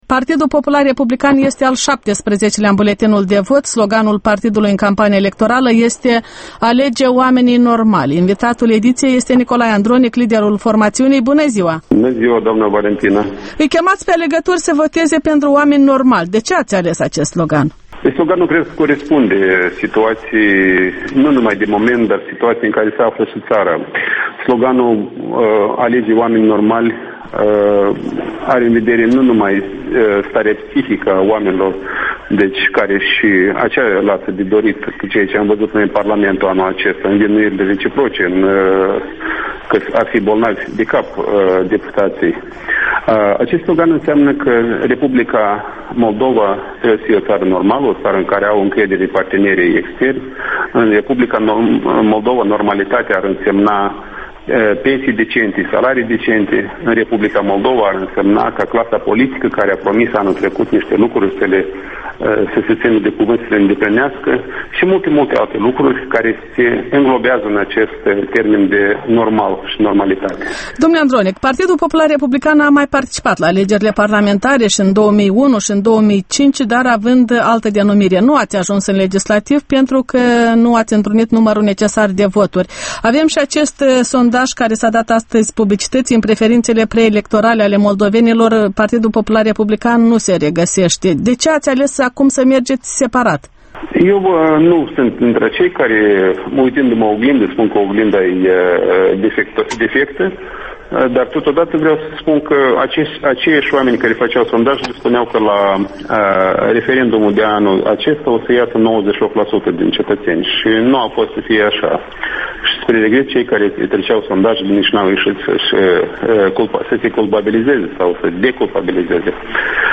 Interviul Electorala 2010: cu Nicolae Andronic